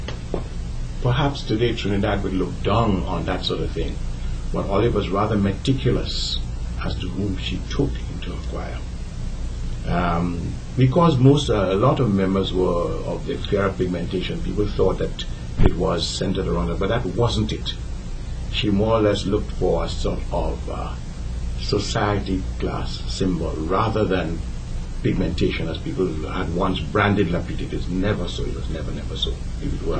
2 audio cassettes